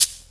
50PERC01  -L.wav